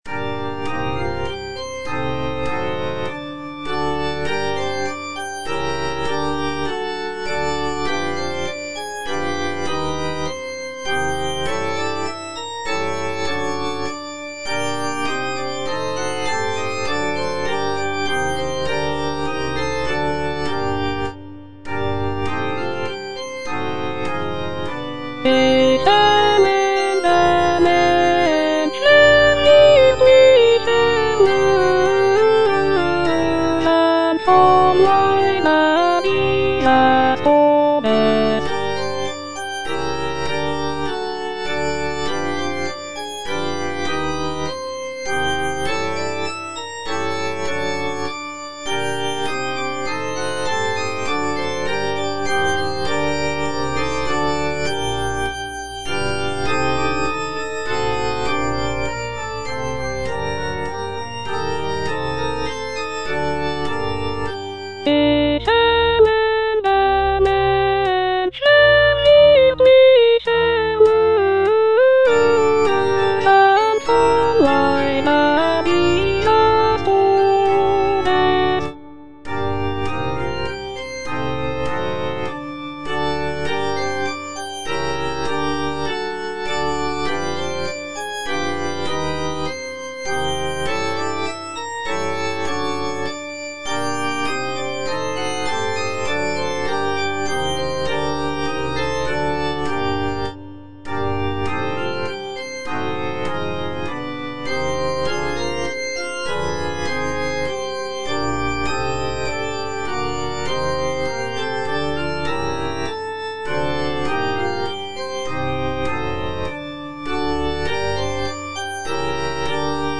Choralplayer playing Cantata
Alto (Voice with metronome) Ads stop